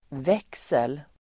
Uttal: [v'ek:sel]